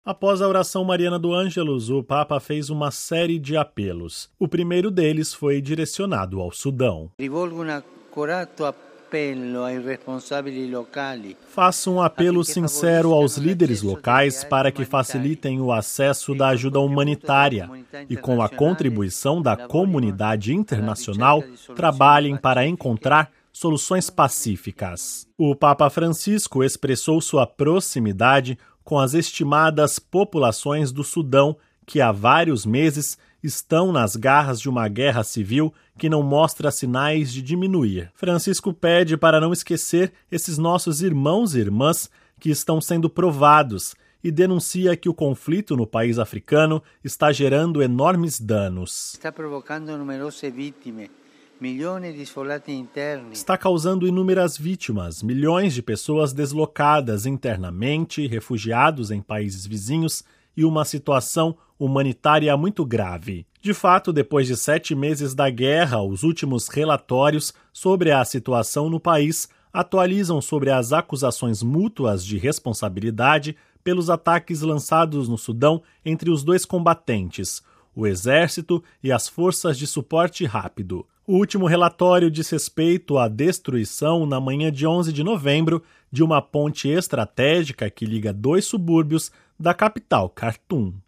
Ouça com a voz do Papa e compartilhe